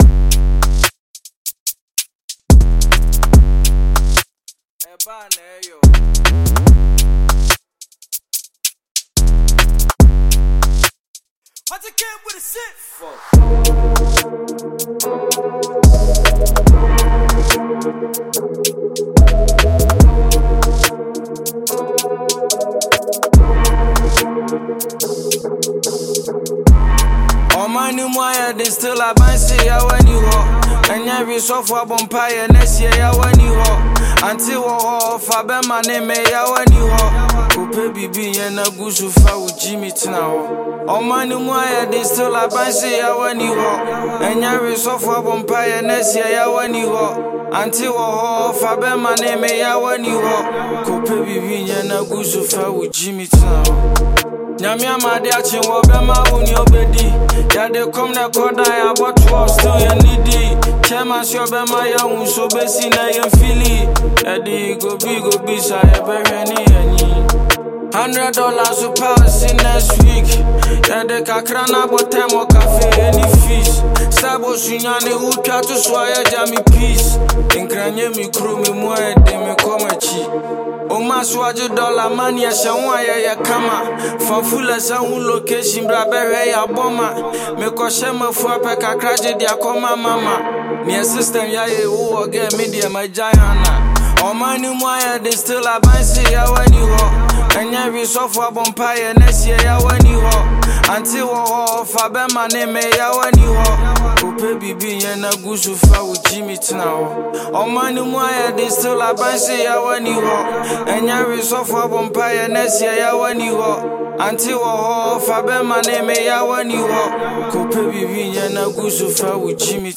Ghanaian multiple award-winning rapper and singer